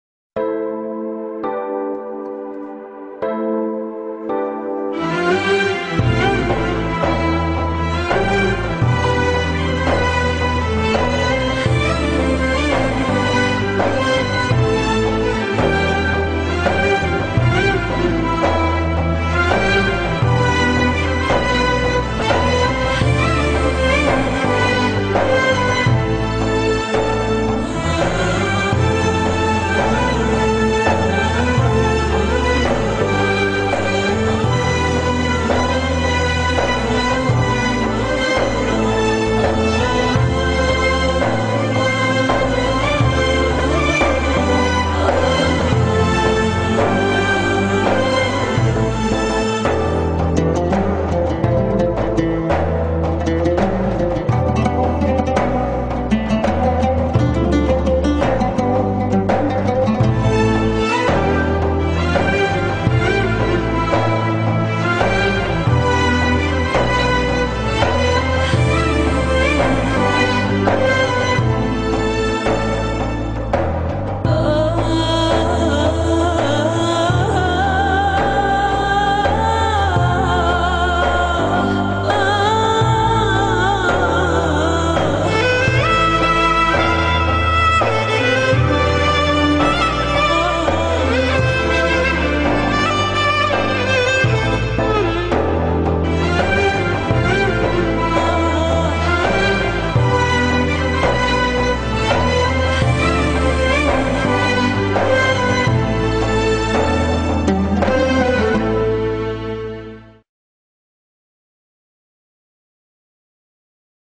بی کلام